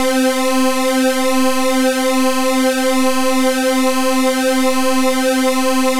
C4_jx_phat_lead_1.wav